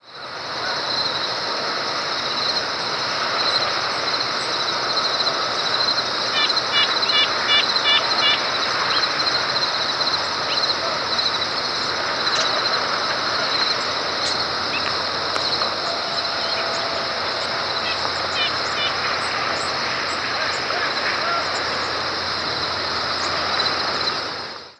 Red-breasted Nuthatch diurnal flight calls
Bird in flight.